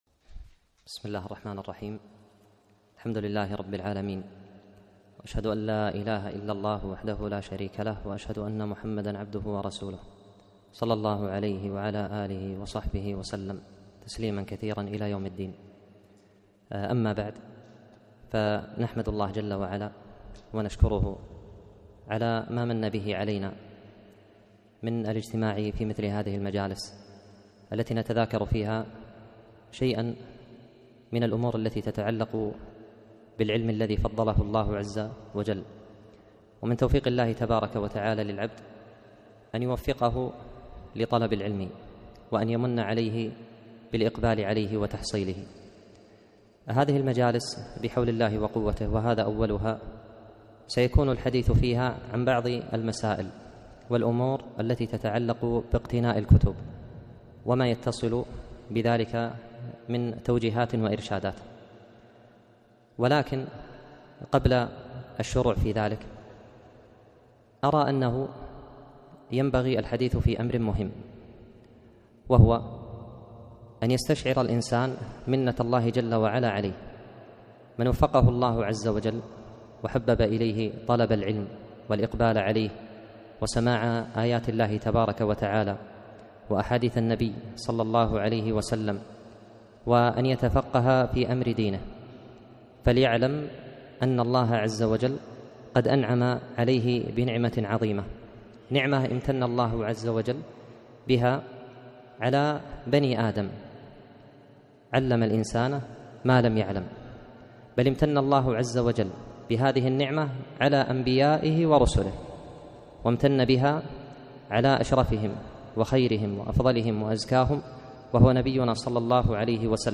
محاضرة - فضل العلم وطلبه - دروس الكويت